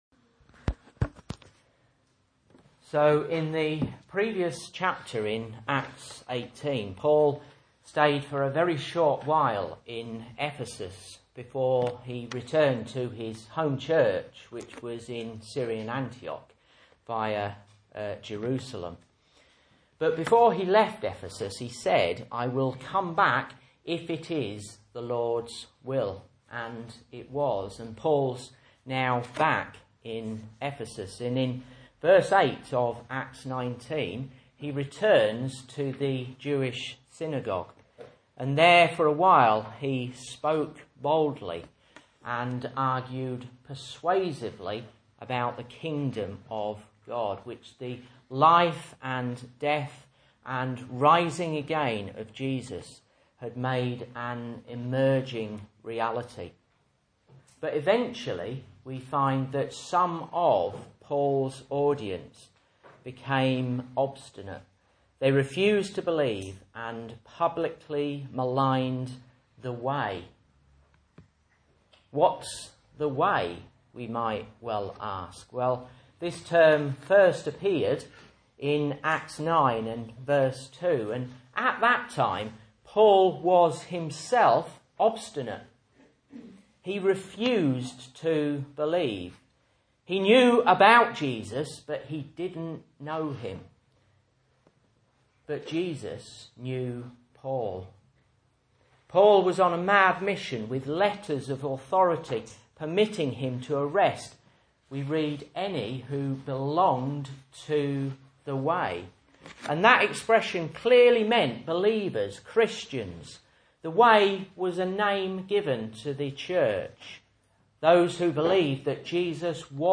Message Scripture: Acts 19:1-19 | Listen